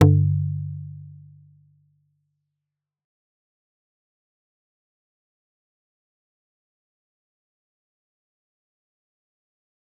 G_Kalimba-G2-f.wav